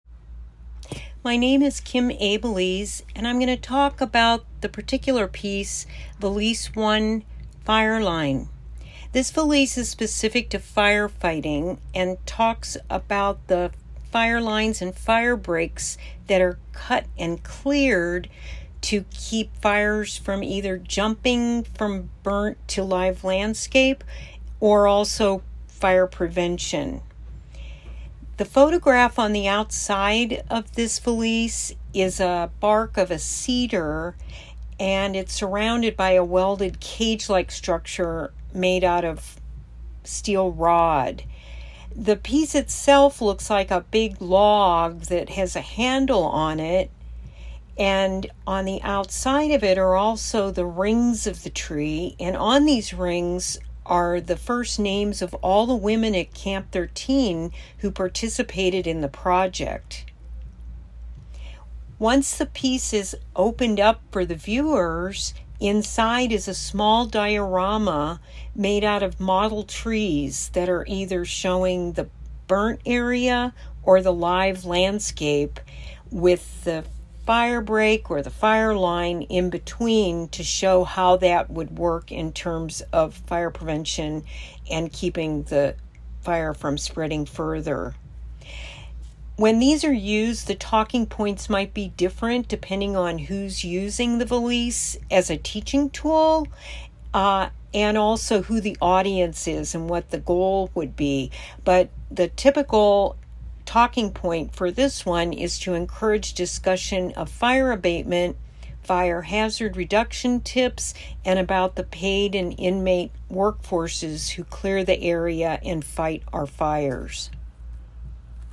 Audio description for Valises for Camp Ground series:
Audio Description: